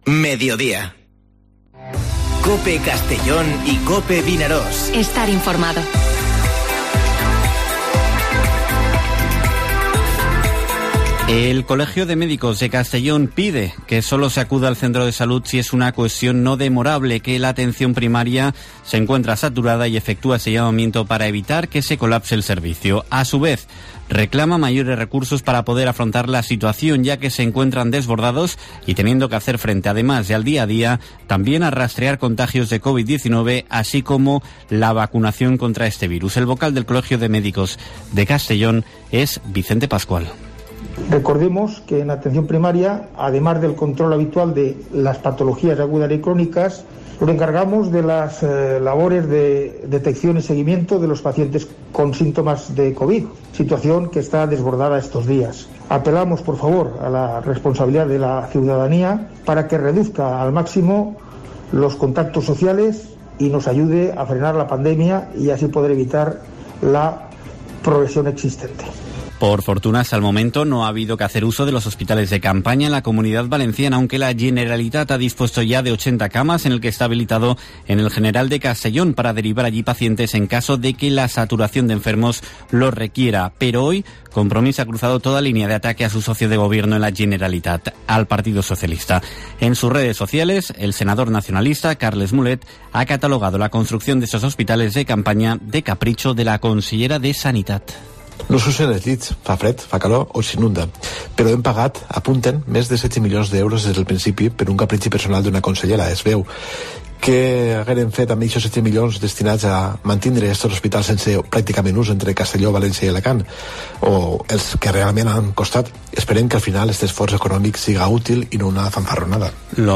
Informativo Mediodía COPE en la provincia de Castellón (14/01/2021)